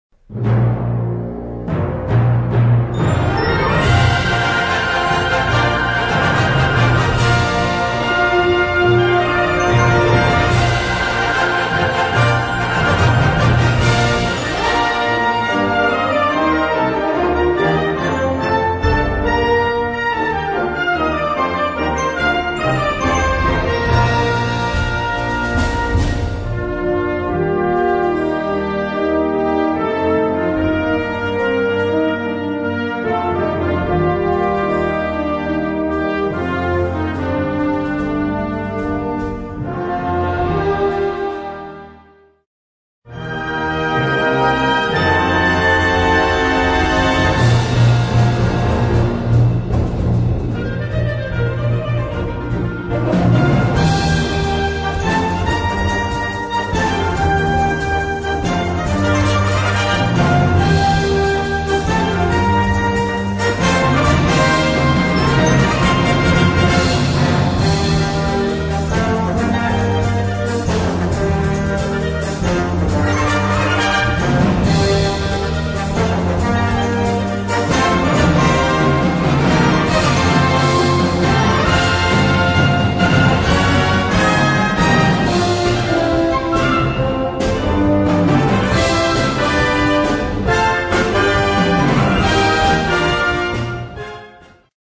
Partitions pour brass band.